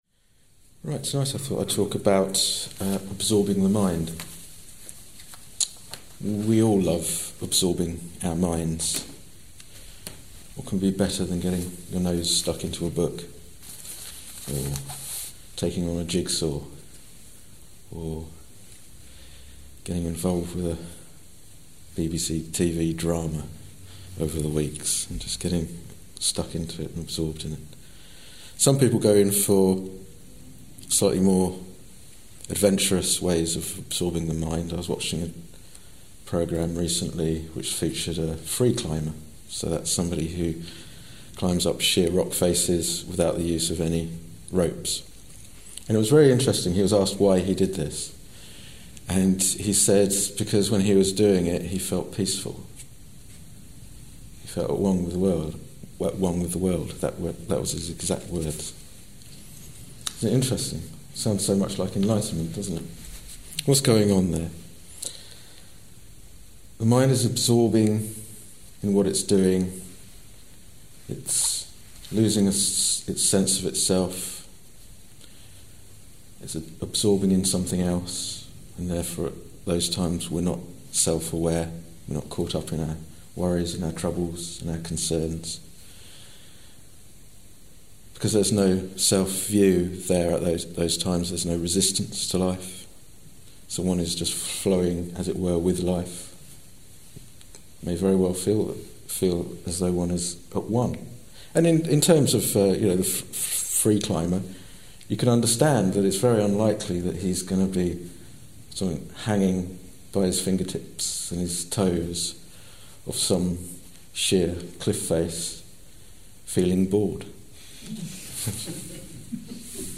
This talk was given in May 2016